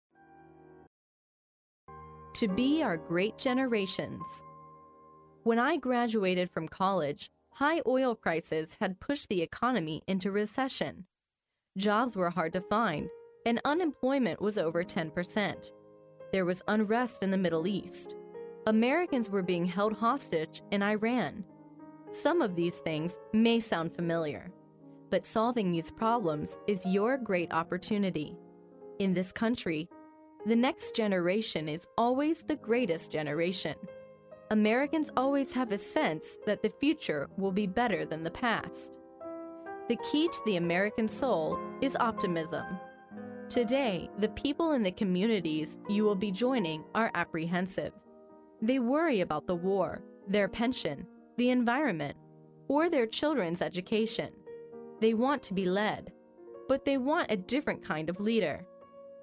在线英语听力室历史英雄名人演讲 第29期:成为我们的最伟大一代(1)的听力文件下载, 《历史英雄名人演讲》栏目收录了国家领袖、政治人物、商界精英和作家记者艺人在重大场合的演讲，展现了伟人、精英的睿智。